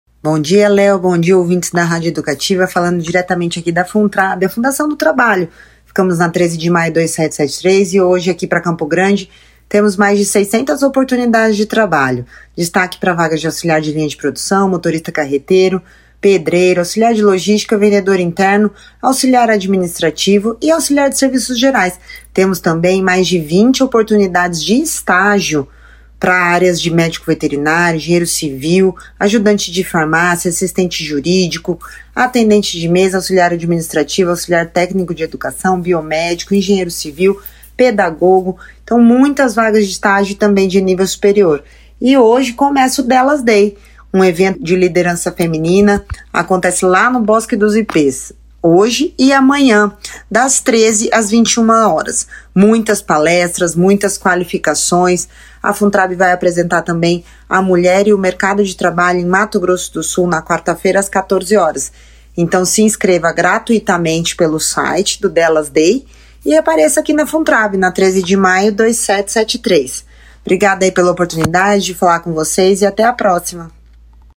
A diretora-presidente da Funtrab, Marina Dobashi, comentou sobre as oportunidades disponíveis nesta semana, ressaltando a importância de os interessados se prepararem com a documentação necessária.